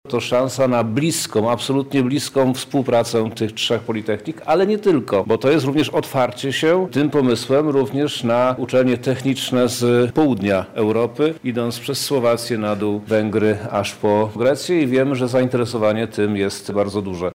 -mówi minister edukacji i nauki Przemysław Czarnek